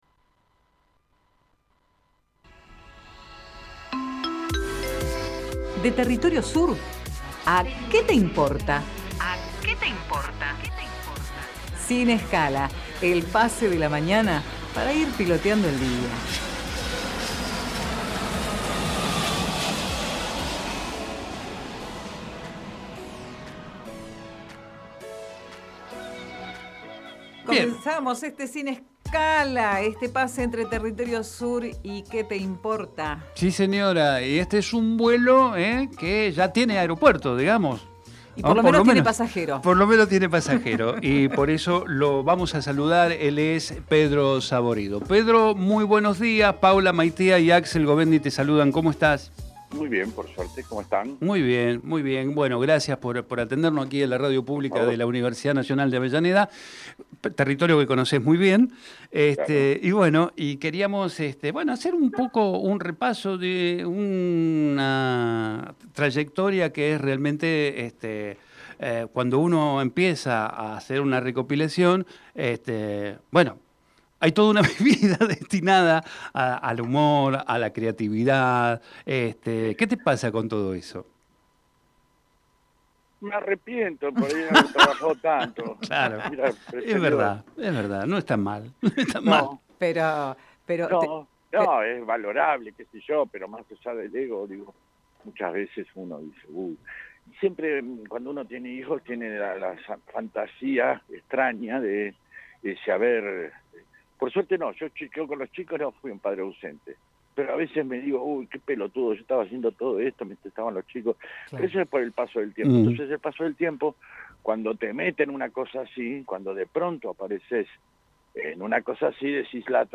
TERRITORIO SUR - QUÉ TE IMPORTA- PEDRO SABORIDO Texto de la nota: Compartimos entrevista en (Territorio Sur - Qué te importa) a Pedro Saborido. Escritor, guionista, productor y director de radio, teatro, cine y televisión argentino.